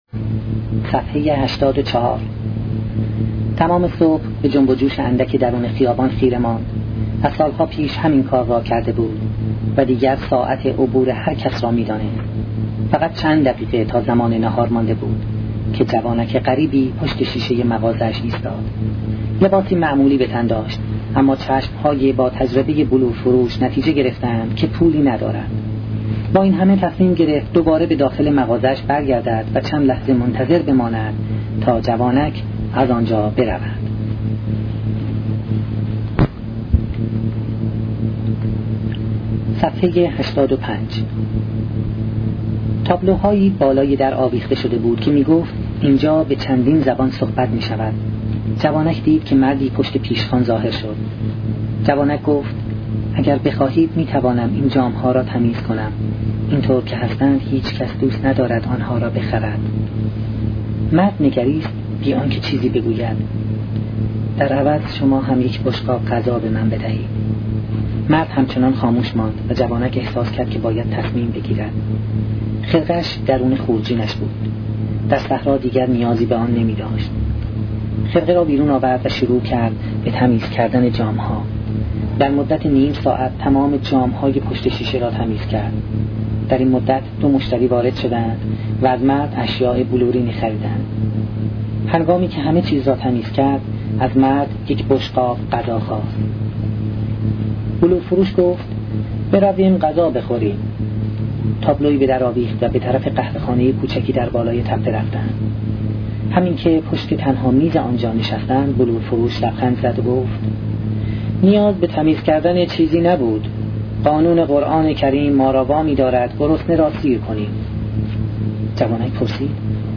کتاب صوتی کیمیاگر